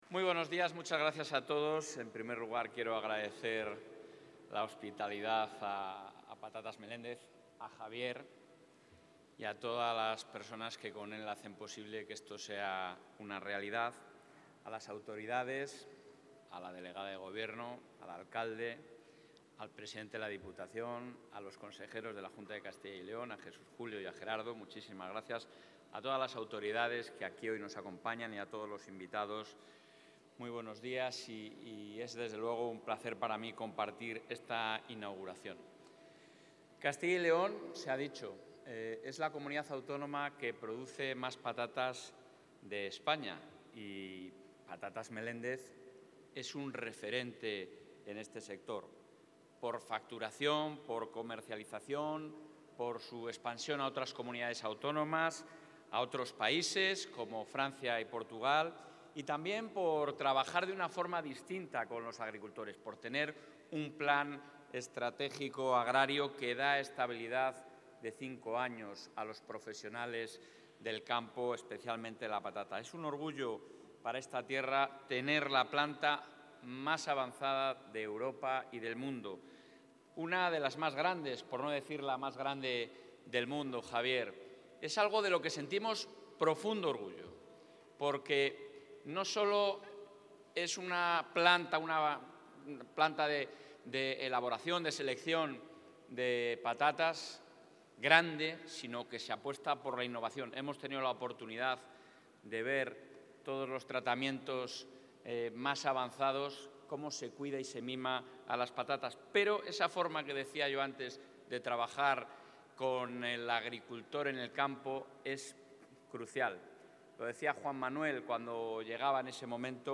Intervención del presidente de la Junta.
El presidente autonómico ha asistido a la inauguración de las nuevas instalaciones de Patatas Meléndez, en la localidad vallisoletana de Medina del Campo, que cuentan con el apoyo de la Junta